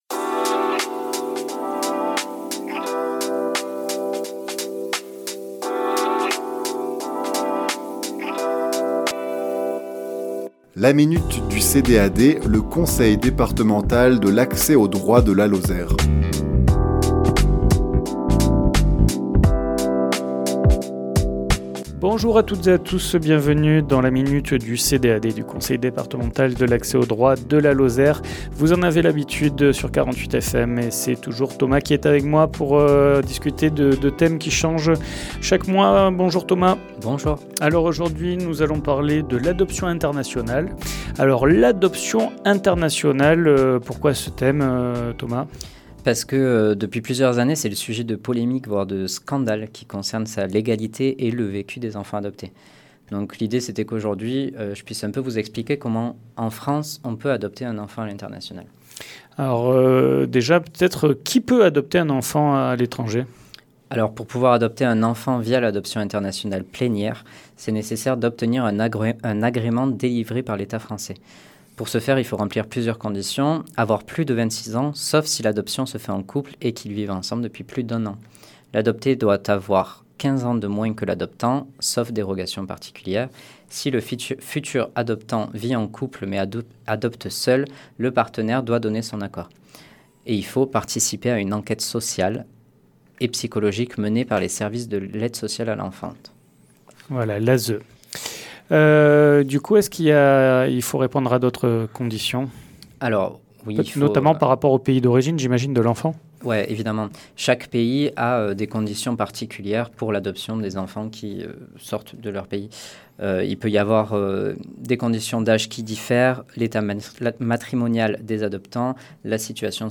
Chronique diffusée le lundi 20 avril à 11h00 et 17h10